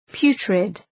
Shkrimi fonetik {‘pju:trıd}
putrid.mp3